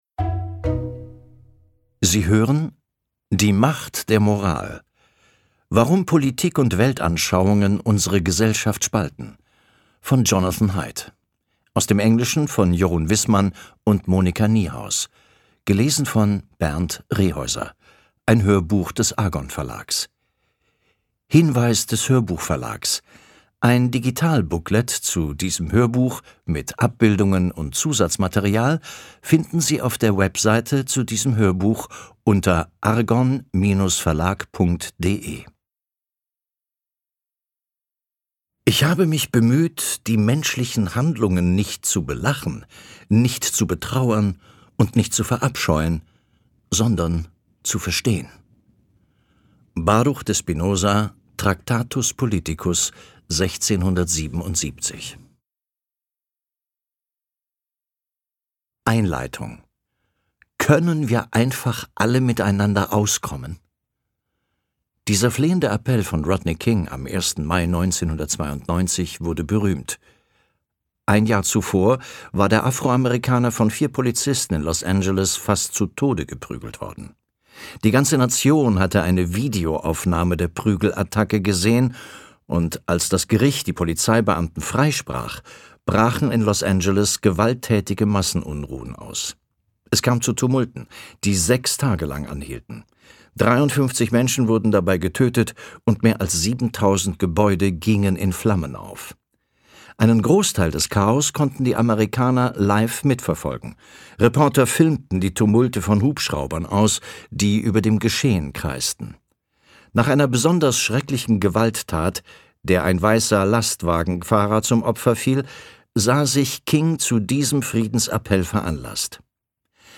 Die Macht der Moral - Jonathan Haidt | argon hörbuch
Gekürzt Autorisierte, d.h. von Autor:innen und / oder Verlagen freigegebene, bearbeitete Fassung.